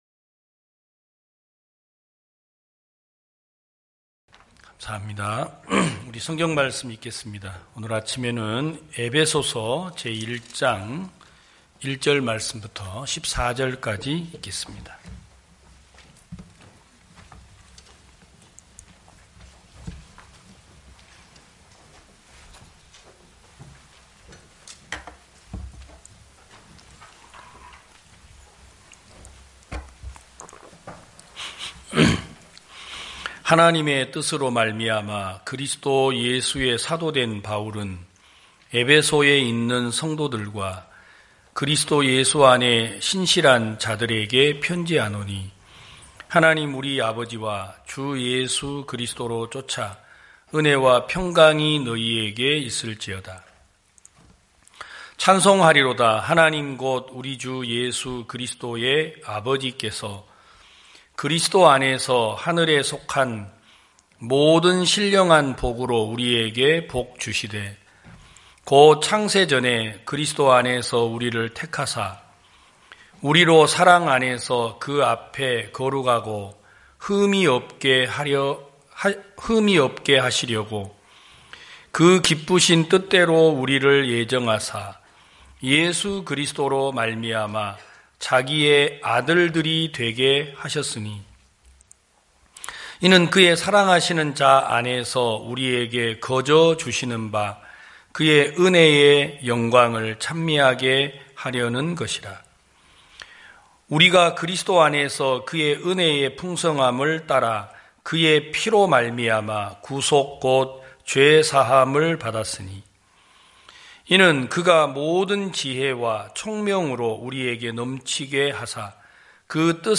2022년 03월 06일 기쁜소식부산대연교회 주일오전예배
성도들이 모두 교회에 모여 말씀을 듣는 주일 예배의 설교는, 한 주간 우리 마음을 채웠던 생각을 내려두고 하나님의 말씀으로 가득 채우는 시간입니다.